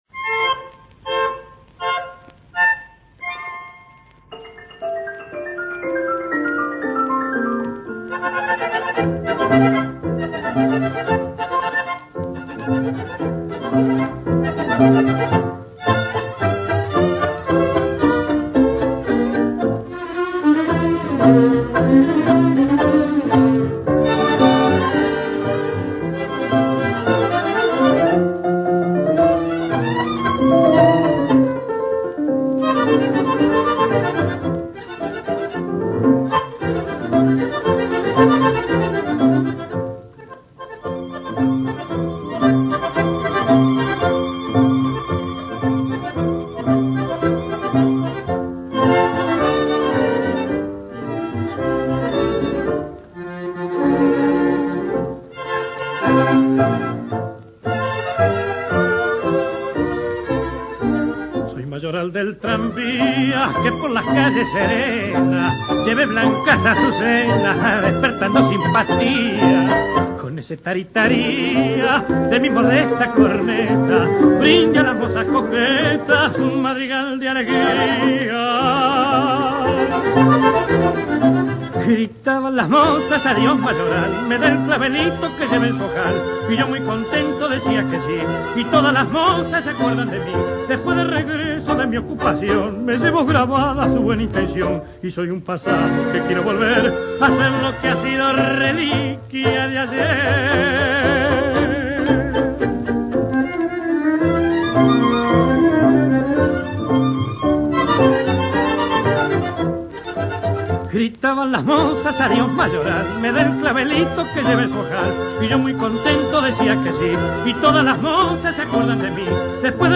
C’est une milonga et la fleur, pourrait être la danseuse.